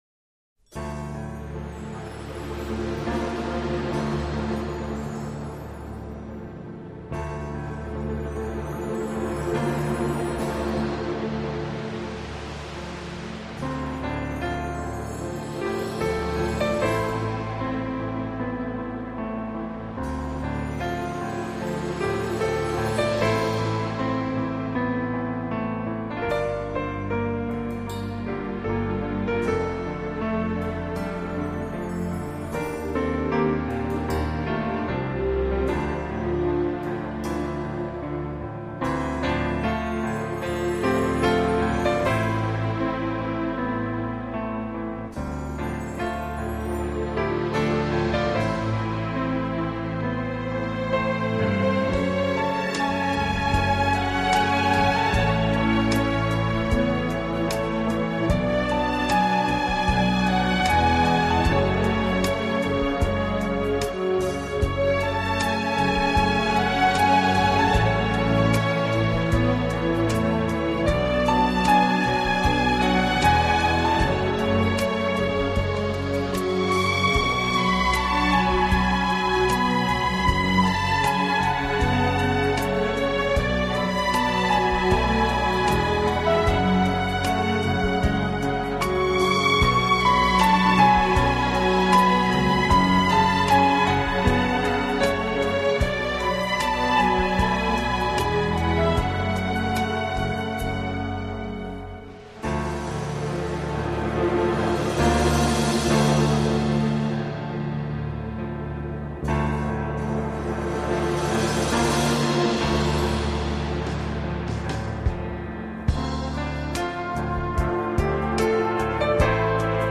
【顶级轻音乐】
世界三大轻音乐团